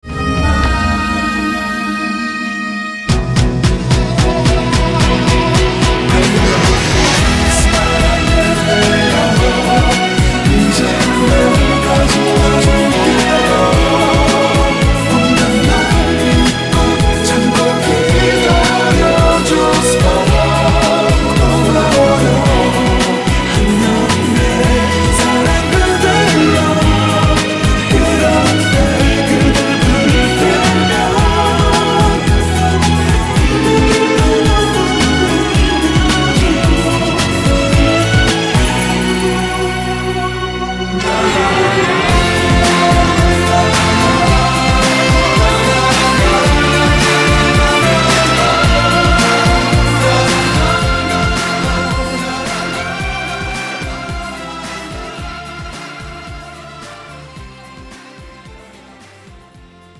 음정 코러스 -1키
장르 축가 구분 Pro MR